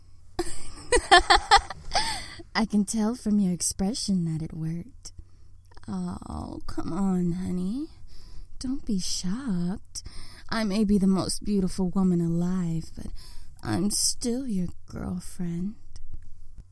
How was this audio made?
Beauty-Sample-Bass-2.mp3